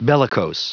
Prononciation du mot : bellicose